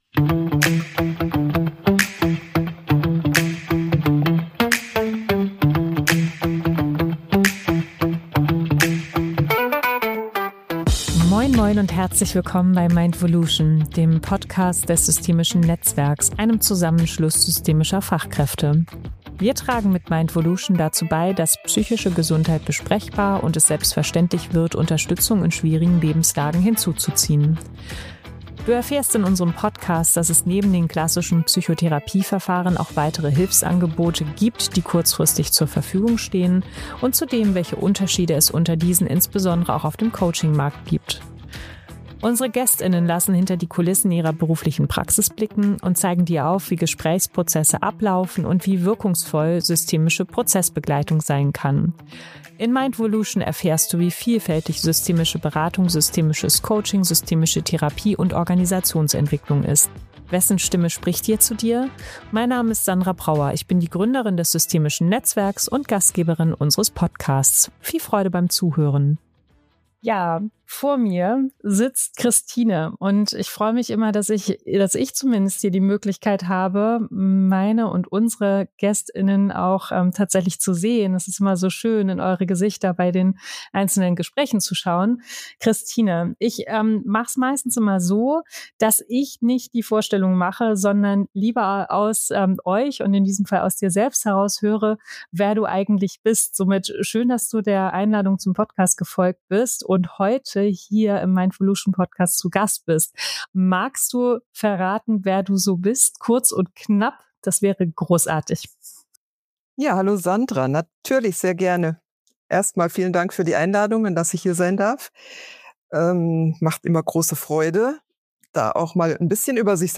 Der Trauer Raum geben - im Gespräch